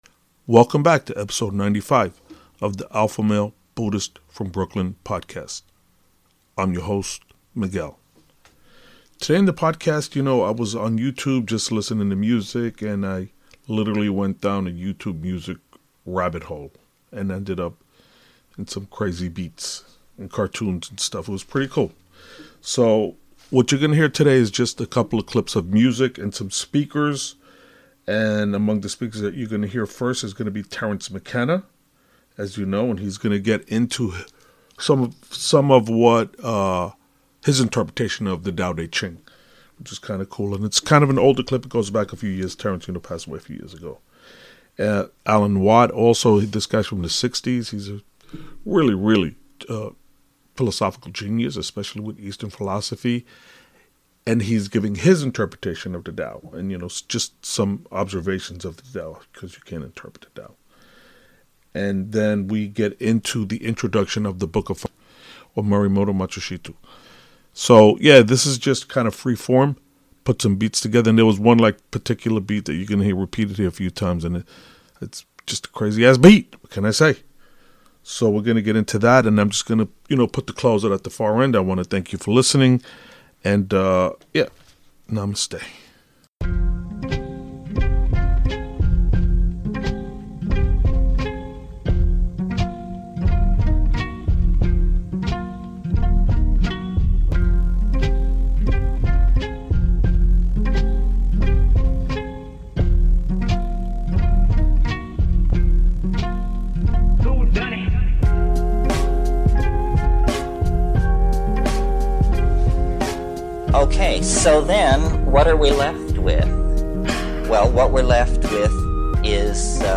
Ep 95 - Knowledge and Hard Beats - Terrence Mckenna - Alan Watts - - Joseph Campbell - Book Of 5 Rings- and some Fat ASS beats